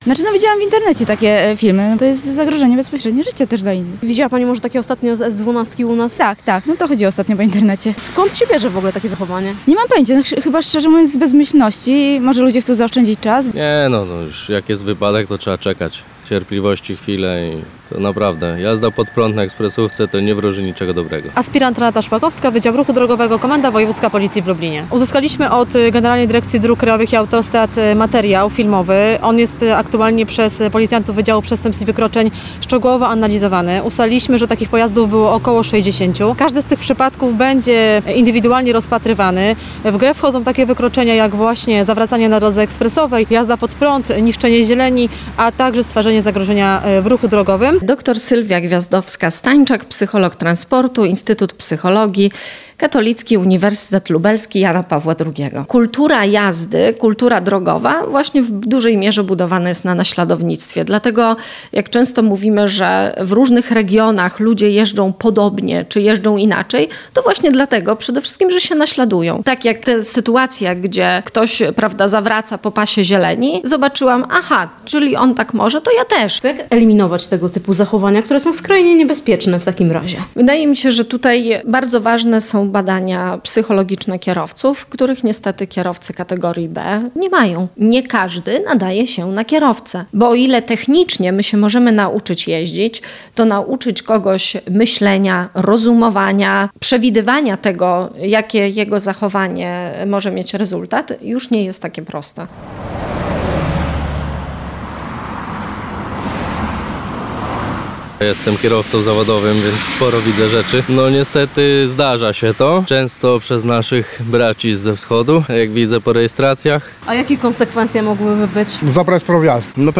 – Trudno sobie wyobrazić, co trzeba myśleć, robiąc w ten sposób – uważają zapytani przez nas kierowcy.